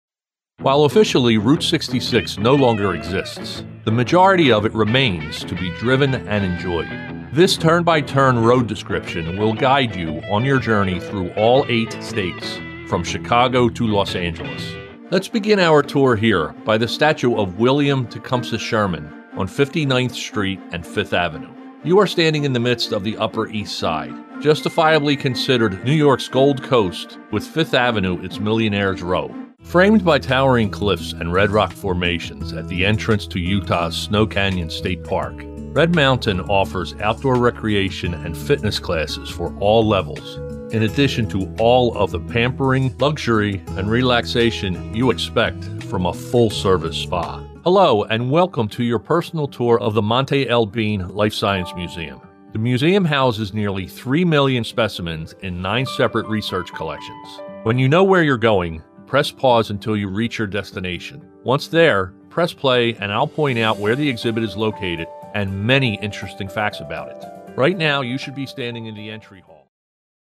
Never AI, always authentic.
Professional-grade equipment and acoustic treatment deliver broadcast-ready audio that rivals major market studios in New York and Los Angeles.
Guided Tours Demo